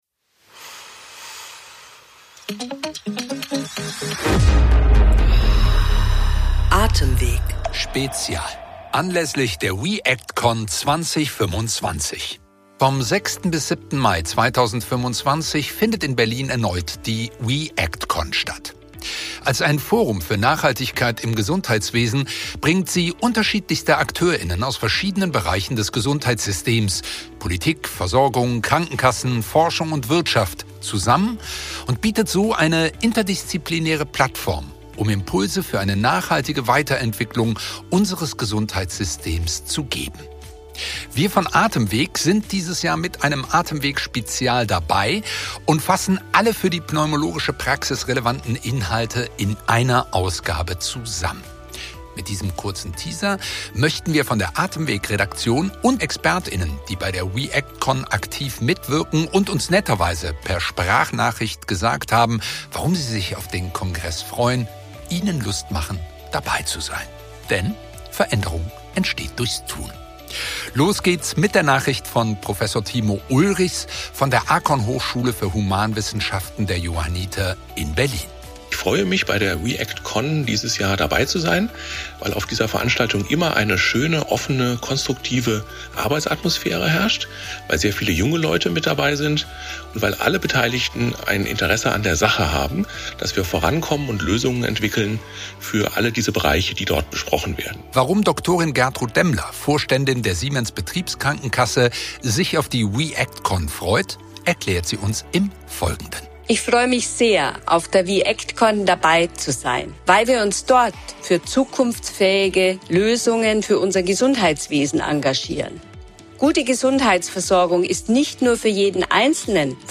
In unserem heutigen Podcast-Teaser berichten Spezialist*innen aus